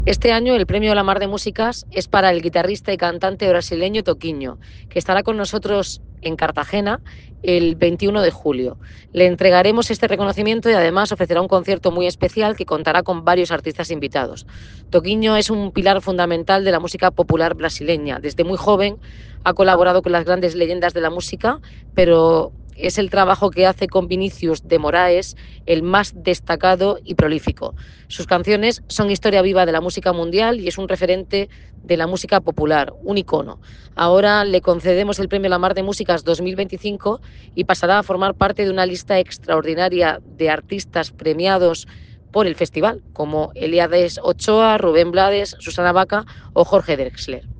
Enlace a Declaraciones de la alcaldesa, Noelia Arroyo, sobre el Premio La Mar de Músicas 2025, Toquinho